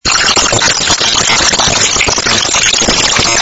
саундтрек из рекламы